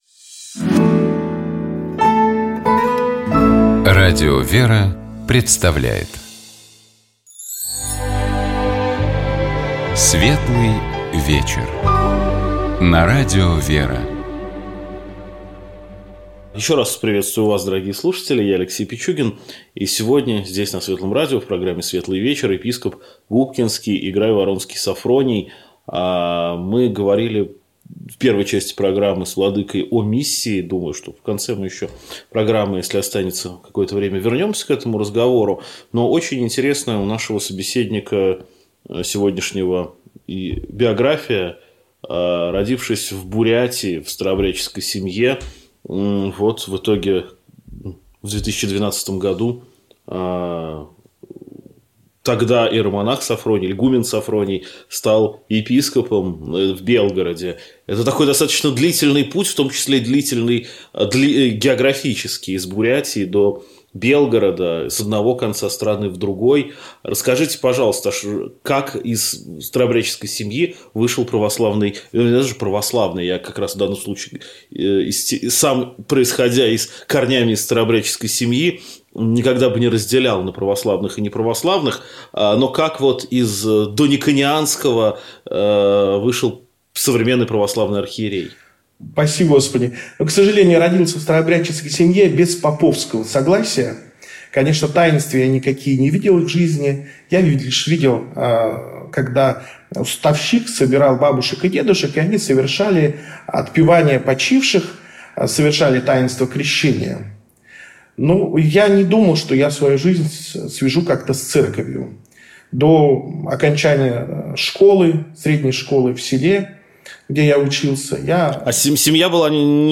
Наш собеседник — епископ Губкинский и Грайворонский Софроний.